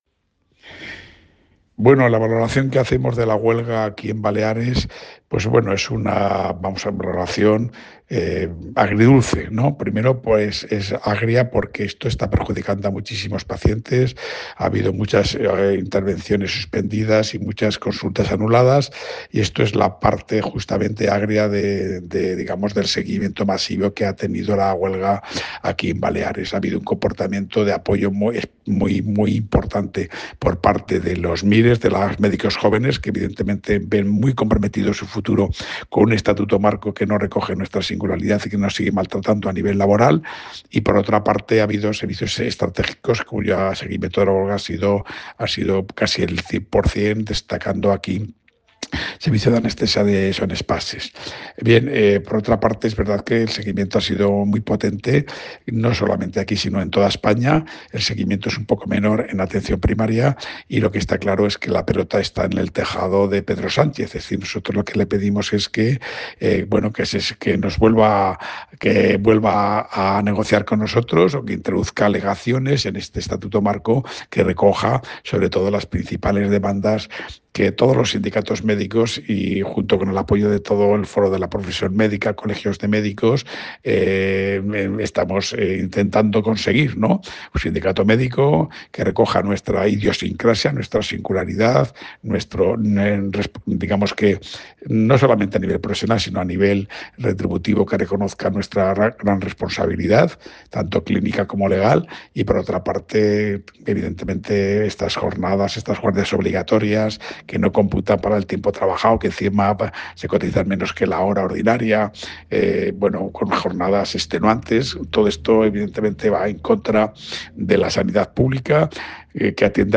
En declaraciones realizadas hoy